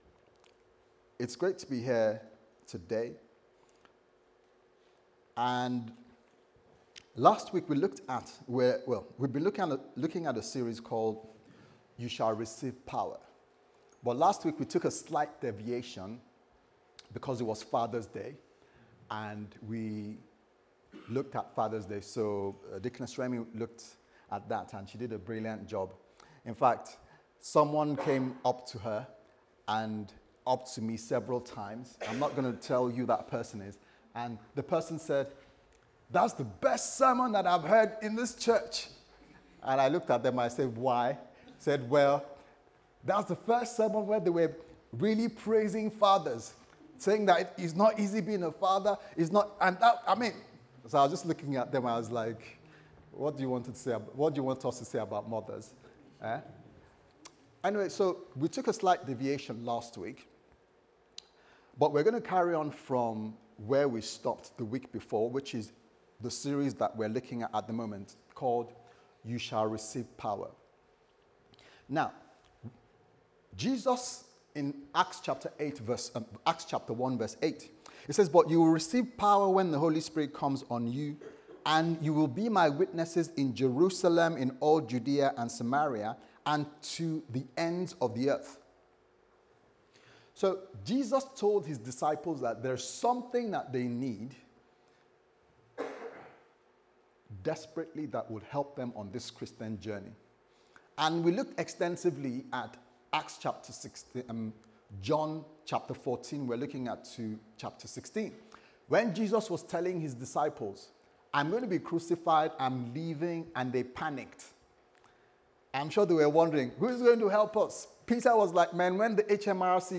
You Shall Receive Power Service Type: Sunday Service Sermon « Resilience